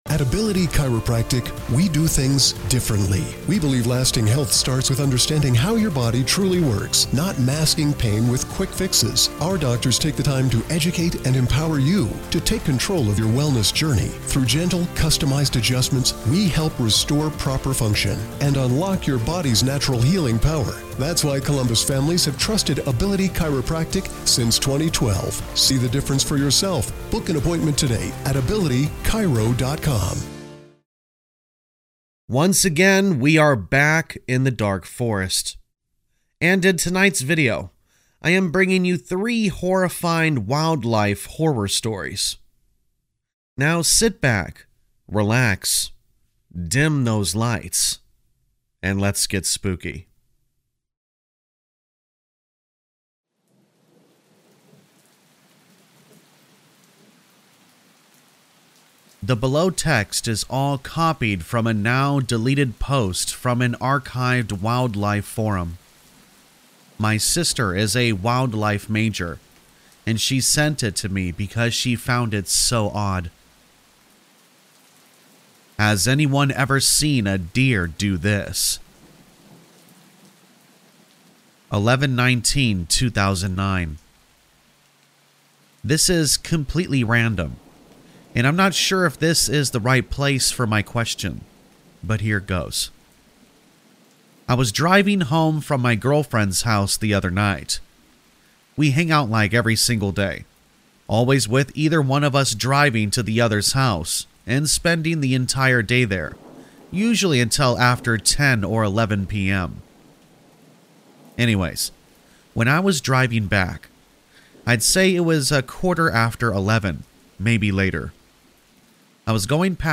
3 TRUE Wildlife Horror Stories | Black Screen For Sleep | Deep Woods Scary Stories | Rain Sounds
All Stories are read with full permission from the authors: Story Credits - 1.